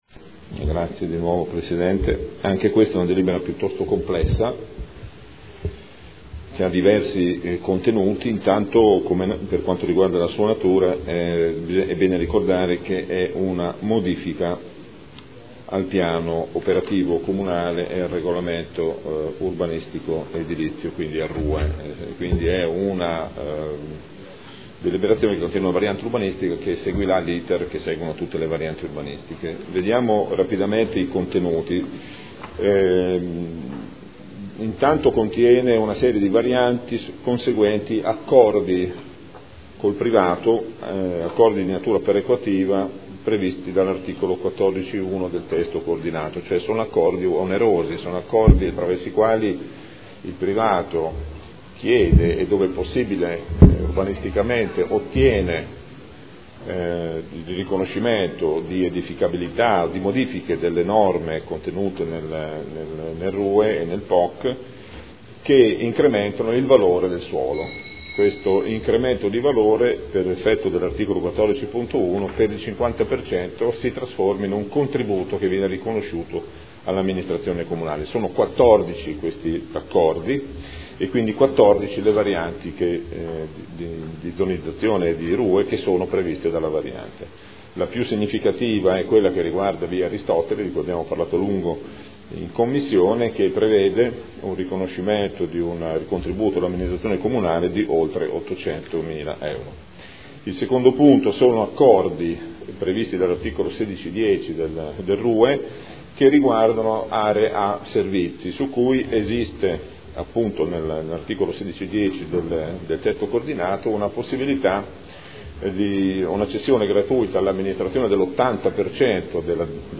Gabriele Giacobazzi — Sito Audio Consiglio Comunale
Seduta del 19 dicembre. Proposta di deliberazione: Variante al Piano Operativo Comunale (POC) e al Regolamento Urbanistico Edilizio (RUE) – Adozione